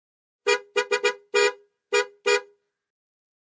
Aston Montego|Ext.|Horns | Sneak On The Lot